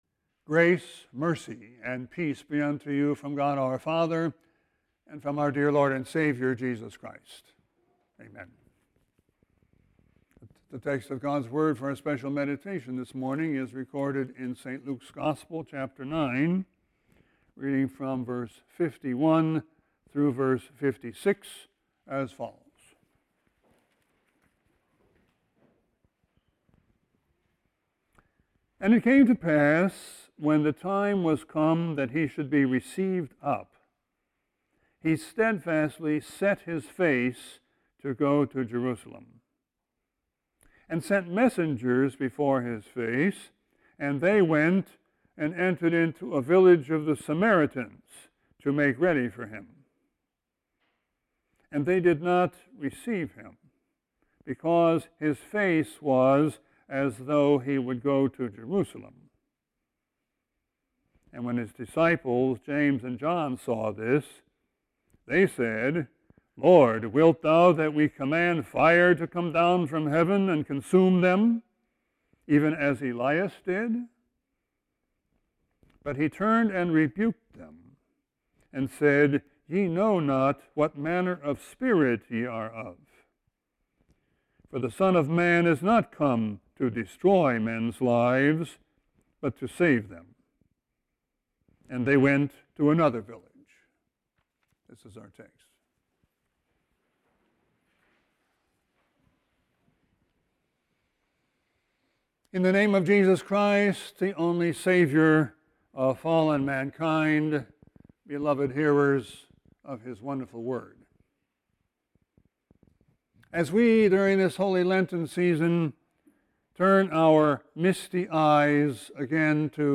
Sermon 3-7-21.mp3